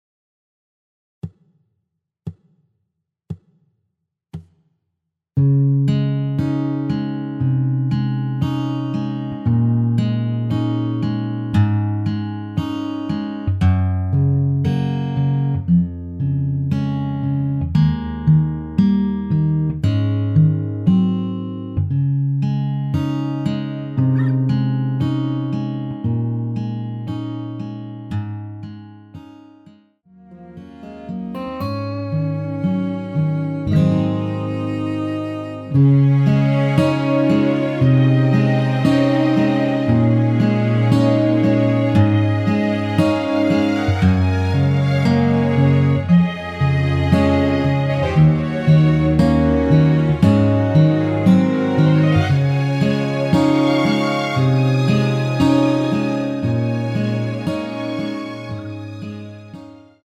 Db
앞부분30초, 뒷부분30초씩 편집해서 올려 드리고 있습니다.